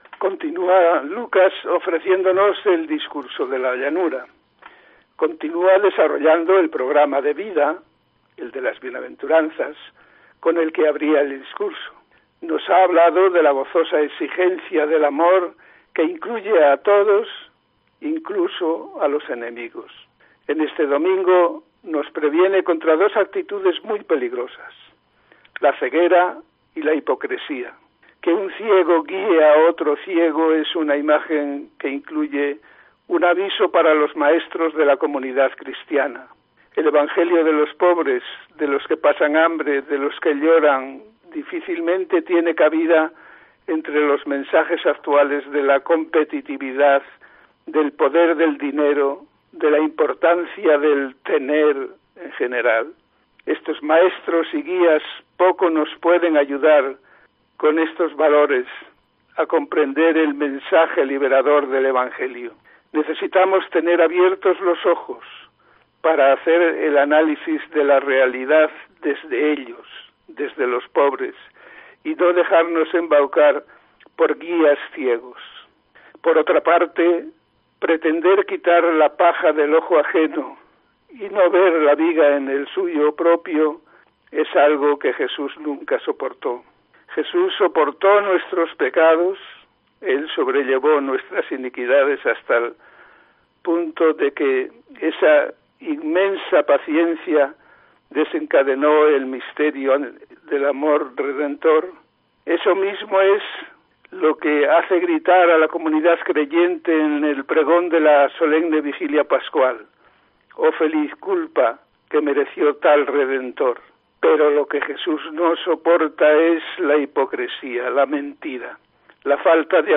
Comentario del Evangelio de este domingo, 27 de febrero de 2022